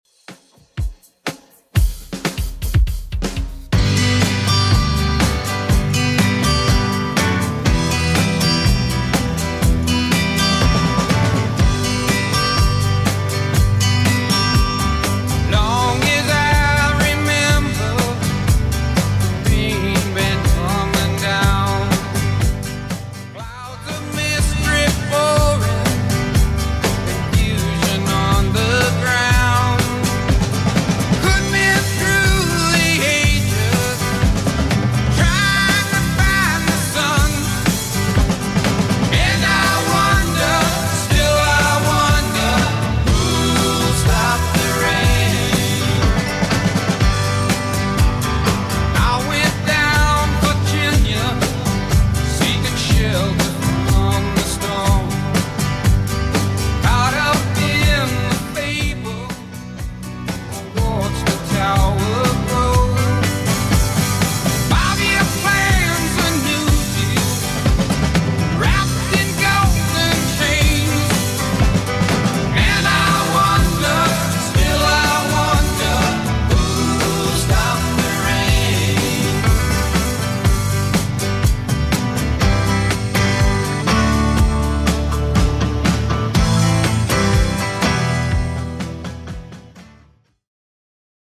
Genre: 80's
BPM: 126